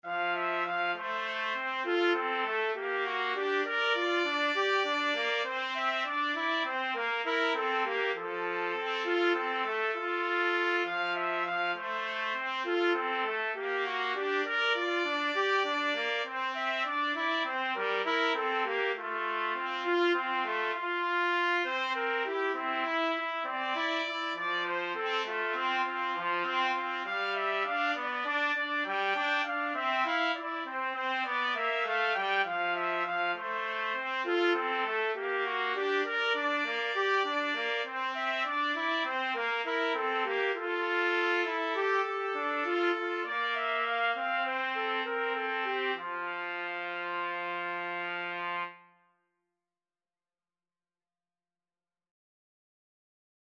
Trumpet 1Trumpet 2
Moderato
9/8 (View more 9/8 Music)